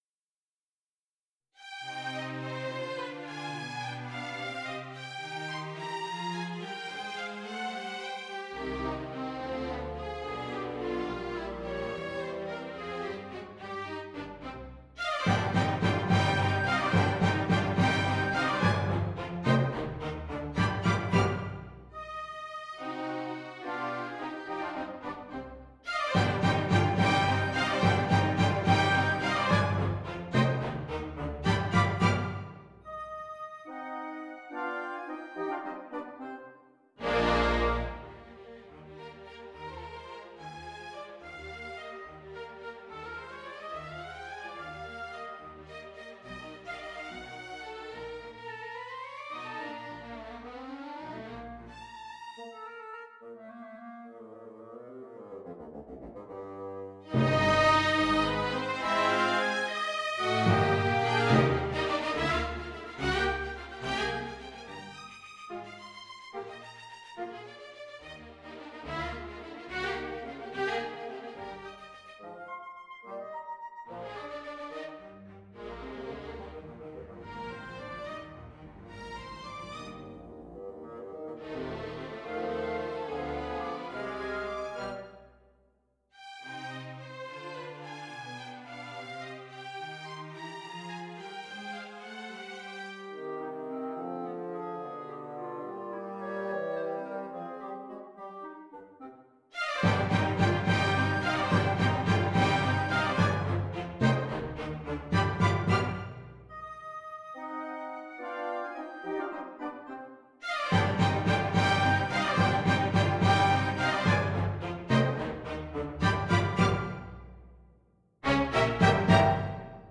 Mozart PianoSonata c-minor KV457 - orchestrated
in Orchestral and Large Ensemble
For both practicing orchestration and just for fun I made this attempt to orchestrate my favourite piano sonata by Mozart which, I think, is well suited for a symphonic setting:
Mozart_PianoSonata-KV457-3_orch.mp3